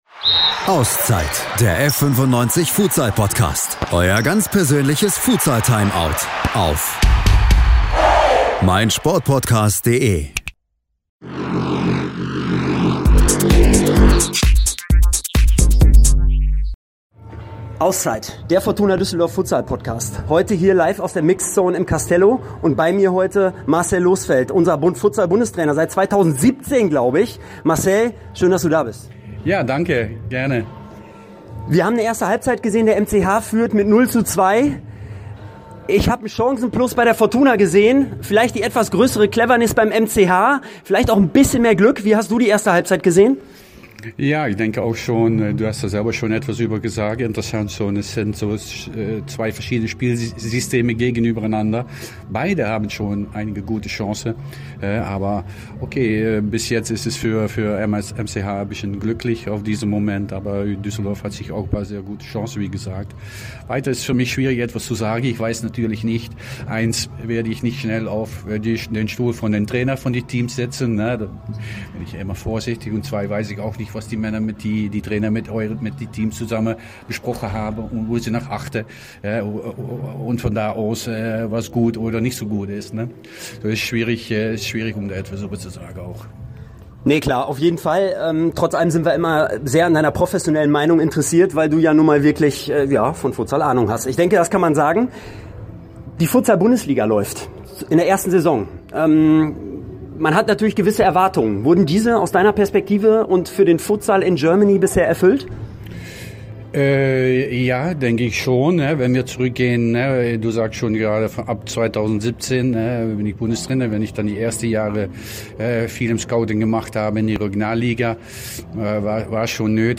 Beschreibung vor 4 Jahren Am vergangenen Samstag standen sich im CASTELLO Düsseldorf zwei alte Bekannte aus der westlichen Futsal Republik gegenüber. Die Fortuna traf auf den MCH FC Sennestadt. Am Ende siegten die Bielefelder zum 7x in Folge, die Fortuna sicherte sich aber dennoch, dank Schützenhilfe der WAKKA Eagles HH, den Klassenerhalt.